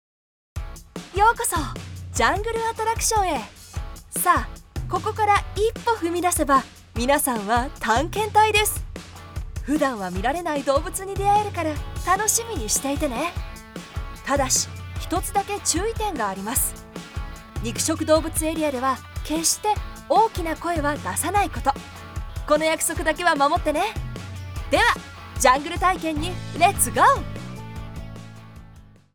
Commercieel, Diep, Vertrouwd, Vriendelijk, Zakelijk
Explainer
Her voice is versatile, stylish, luxurious, authentic, yet believable